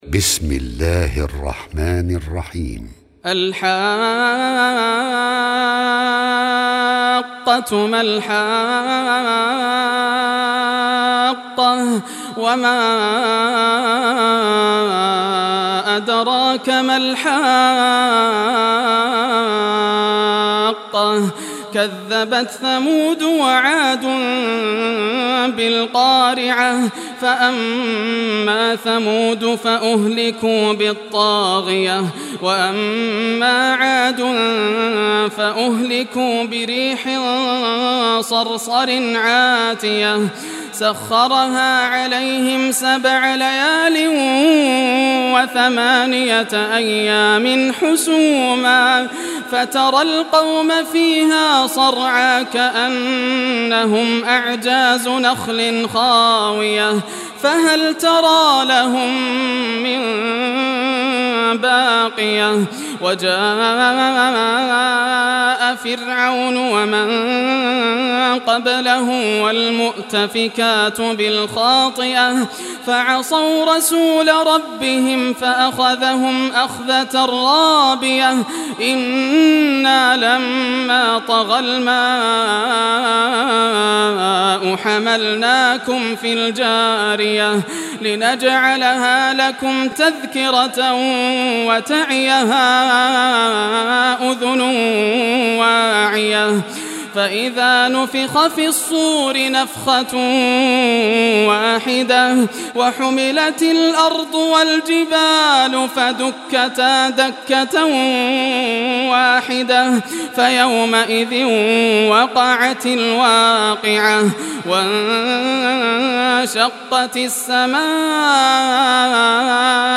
Surah Al-Haqqah Recitation by Yasser al Dosari
69-surah-haqqah.mp3